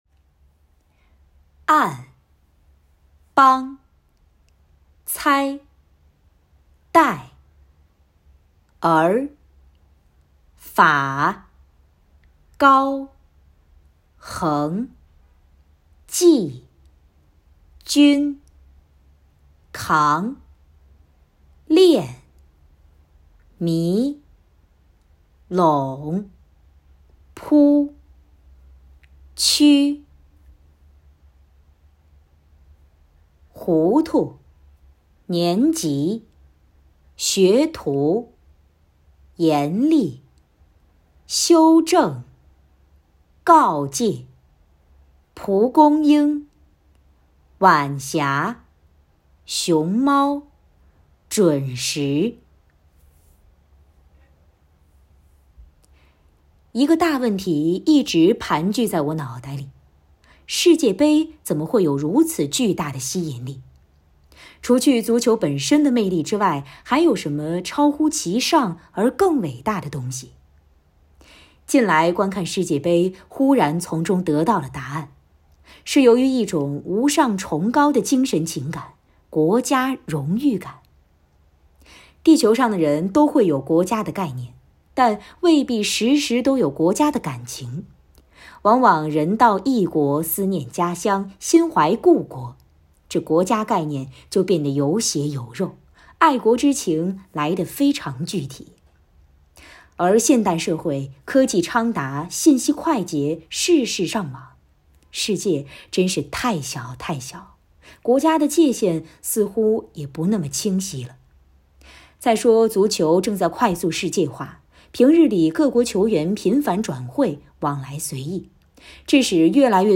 感谢积极参与此次“领读周周学”课件录制的“生活好课堂”朗读专业志愿者！